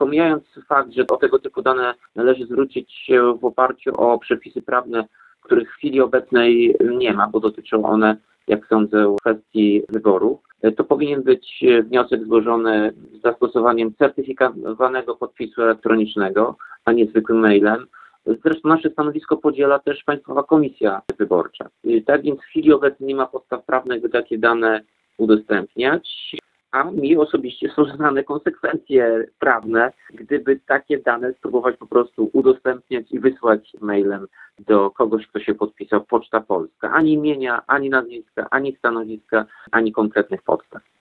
Jak dodaje prezydent Andrukiewicz, mail nie był opatrzony żadnym imiennym podpisem.